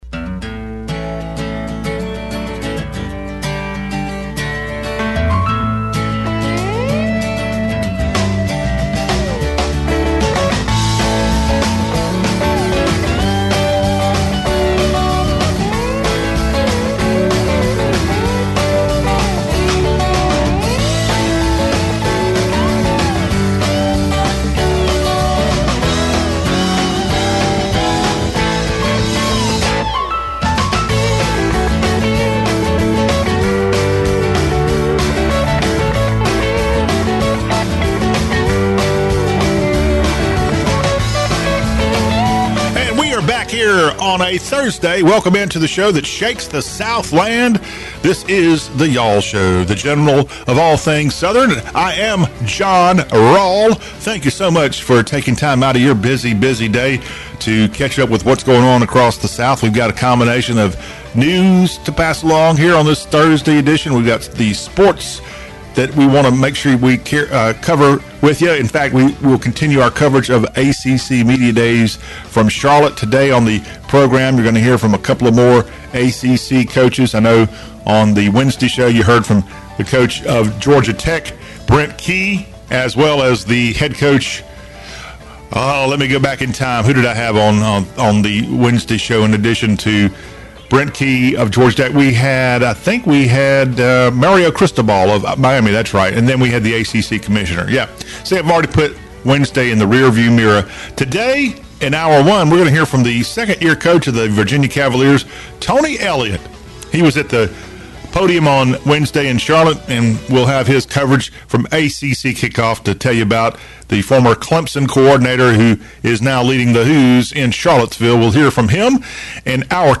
UVa coach Tony Elliott, FSU coach Mike Norvell, and Duke coach and reigning "ACC Coach of the Year" honoree Mike Elko speak about their respective teams at ACC Media Days, Day 2. Plus, a breakdown of states hating other states in today's "Hashtag Hullabaloo."